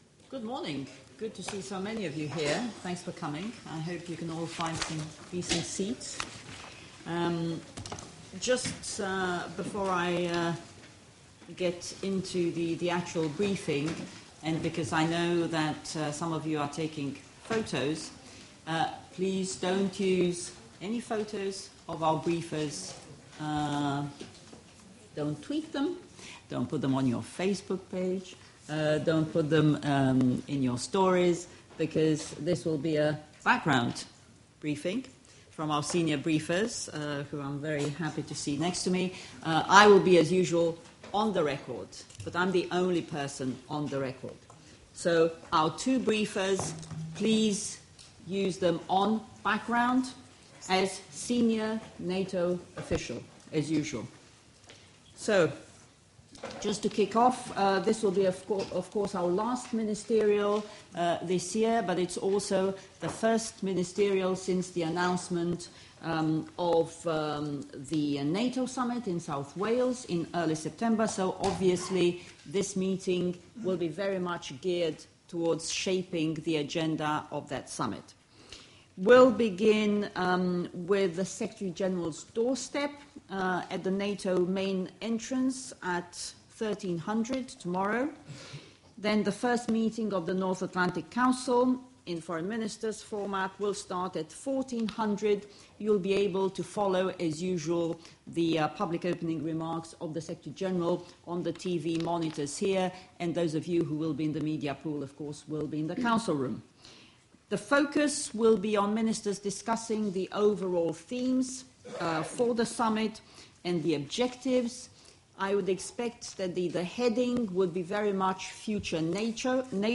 Opening remarks at the pre-ministerial briefing by the NATO Spokesperson, Oana Lungescu